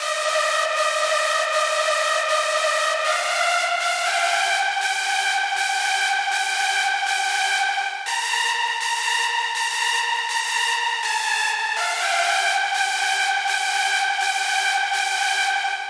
史诗般的房间填充广义EDM合成器
描述：一个非常精致的合成器，不能单独使用。它为混音中的其他合成器提供伴奏。